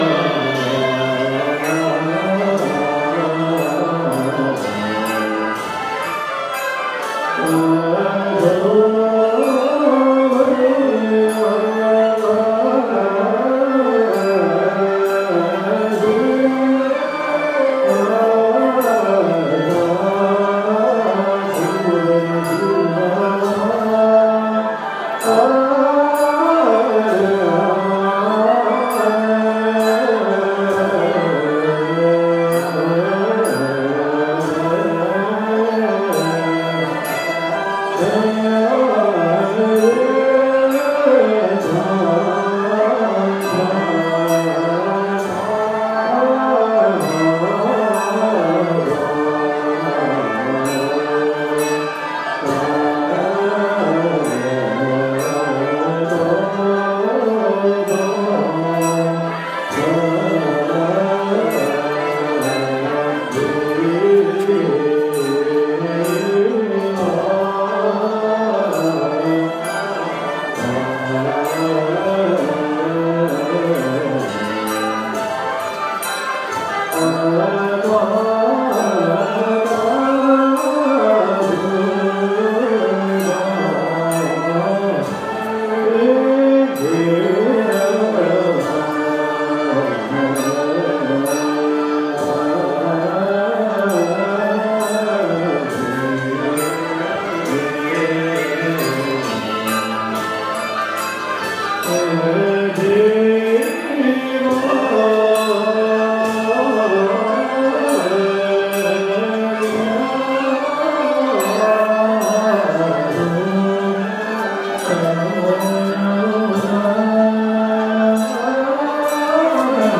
The temple activity swirled around us while the smell of incense and murmurs of prayer recitals filled our senses. We followed the musical monk procession to watch and listen to the entrancing chants of a prayer ceremony.
The sounds of the musical procession.
monk-3.m4a